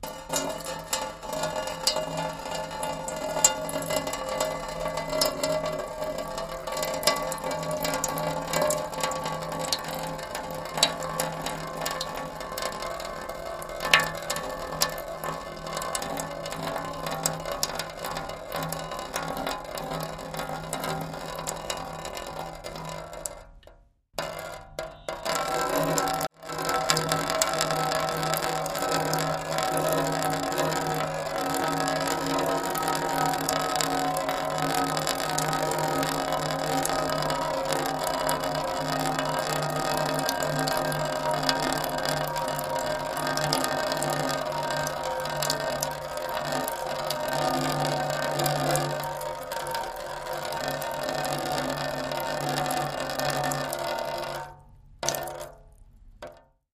Water Trickle On A Metal x2